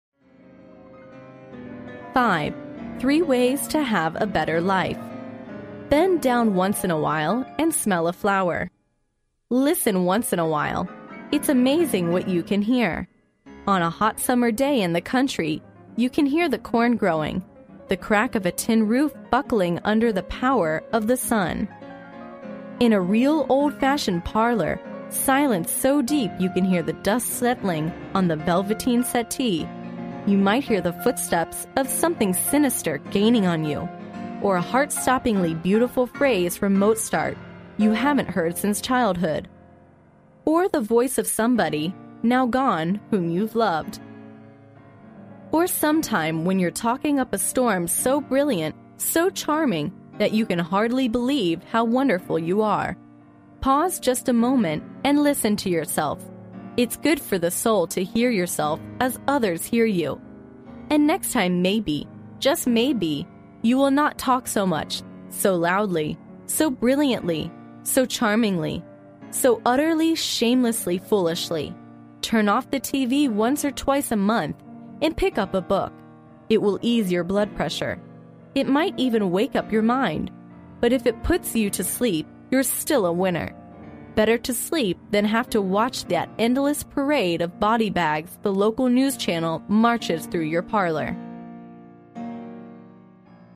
历史英雄名人演讲 第109期:拉塞尔·贝克在康乃迪克学院的演讲:三种方法让你生活更美好 听力文件下载—在线英语听力室